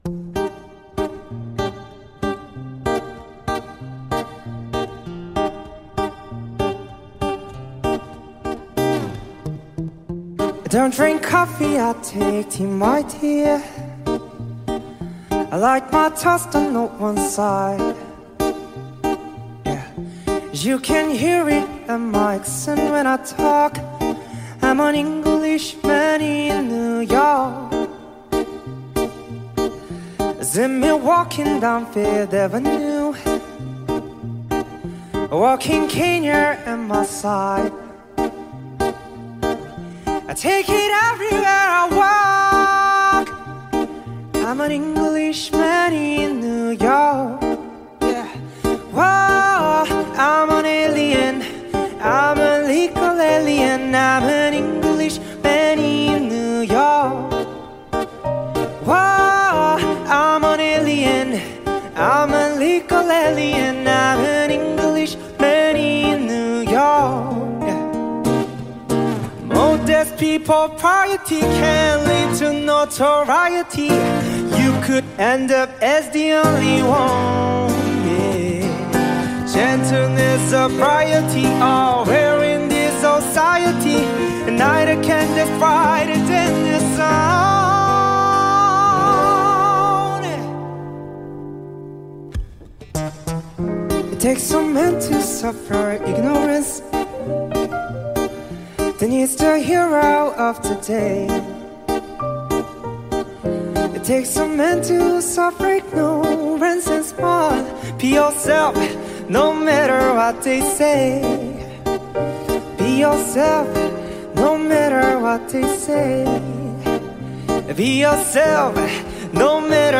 خواننده کره ای